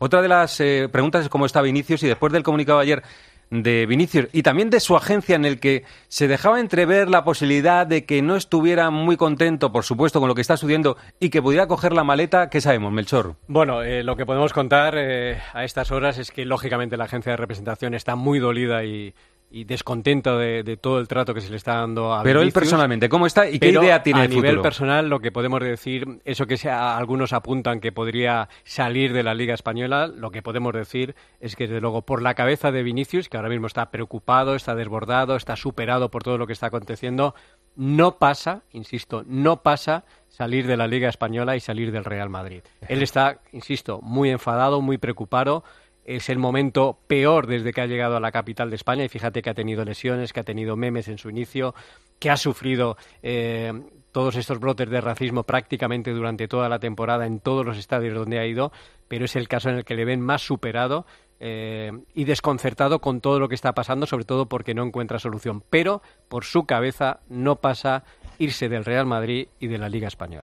Informa Melchor Ruiz